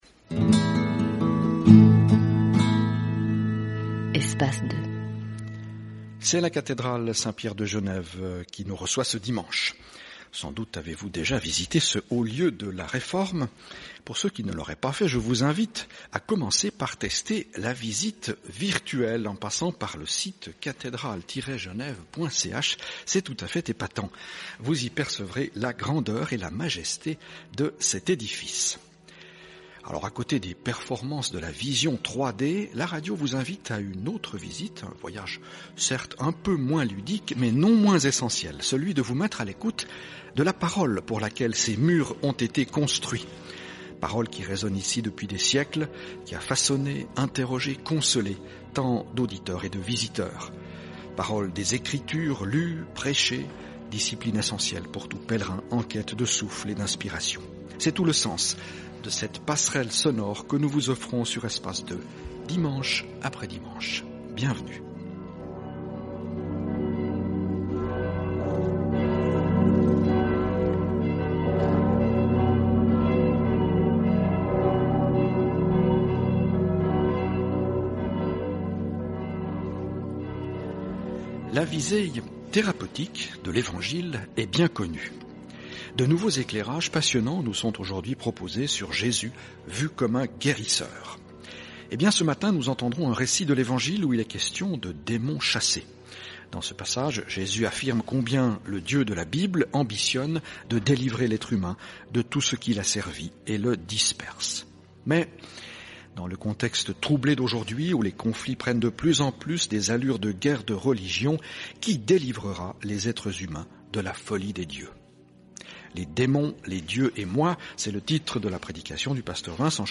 Dans ce passage, Jésus affirme combien le Dieu de la Bible ambitionne de délivrer l’être humain de tout ce qui l’asservit et le disperse. Mais dans le contexte troublé d’aujourd’hui, où les conflits prennent de plus en plus des allures de guerres de religions, qui délivrera les êtres humains de la folie des dieux ? - culte du 3 mai 2015 à Cathédrale Saint-Pierre, Genève